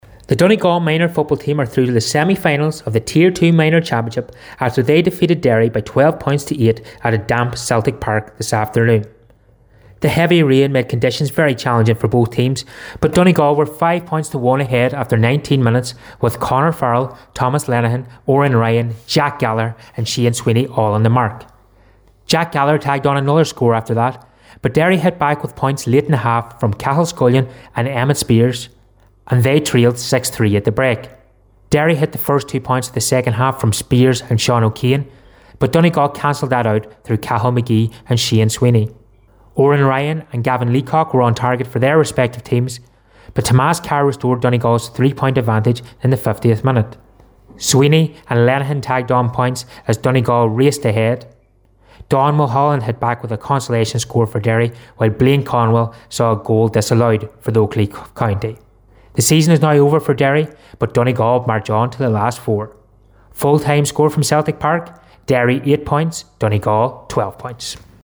With the full time report